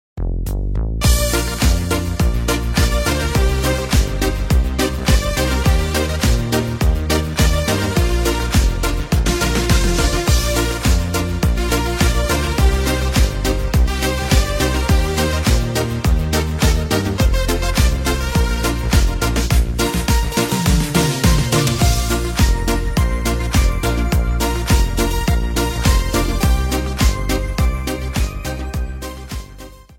Instrumentalny